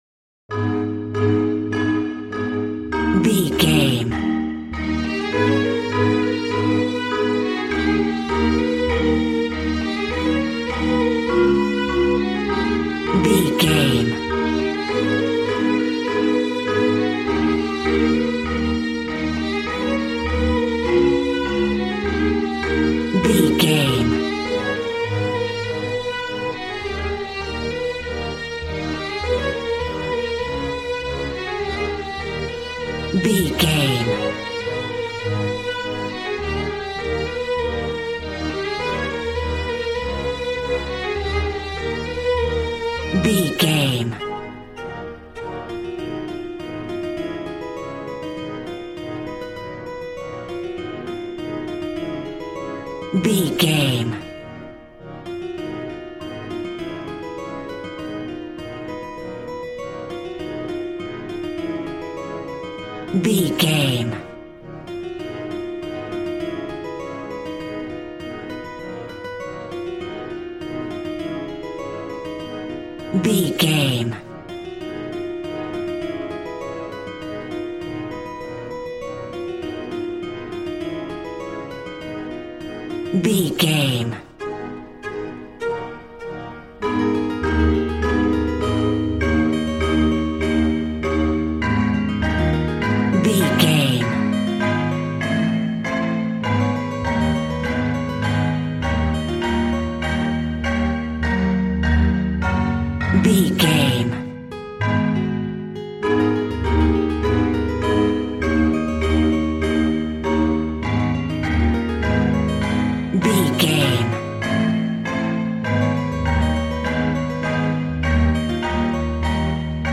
Aeolian/Minor
A♭
cheerful/happy
joyful
drums
acoustic guitar